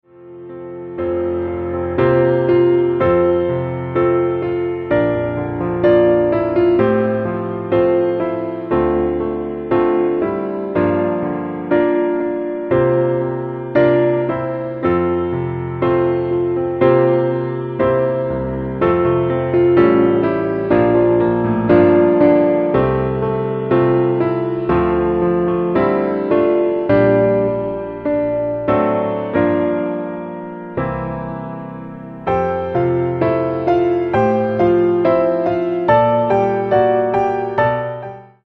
Piano - Low